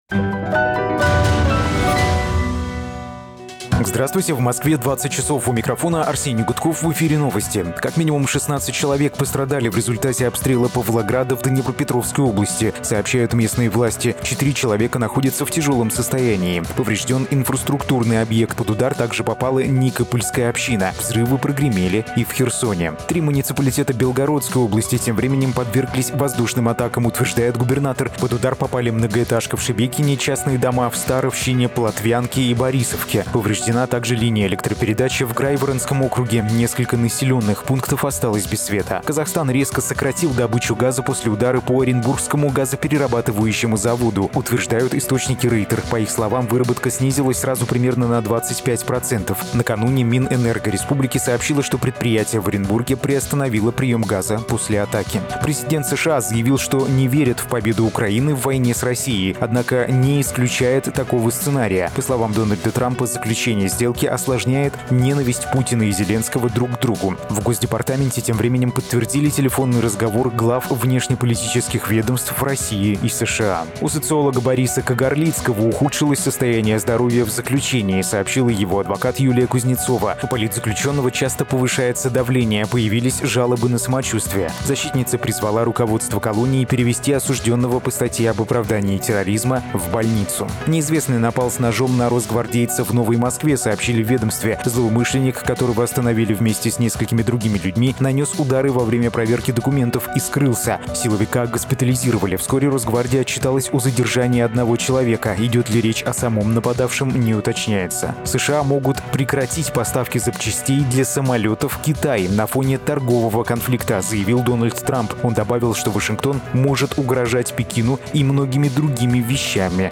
Слушайте свежий выпуск новостей «Эха»
Новости 20:00